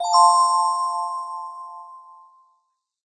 bright_bell_chime.ogg